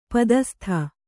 ♪ padastha